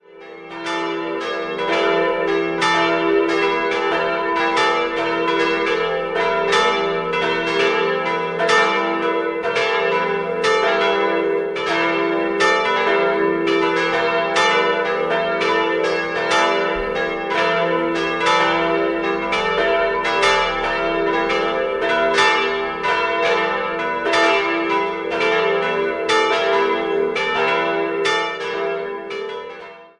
Die Ausmalung im Inneren stellt eine Rekonstruktion der originalen Malereien von Max Fürst dar. 4-stimmiges Geläut: e'-g'-a'-h' Die kleine Glocke wurde 1923 von Johann Hahn in Landshut gegossen, die drei größeren wurden 1947 bzw. 1949 von Karl Czudnochowsky in Erding gegossen.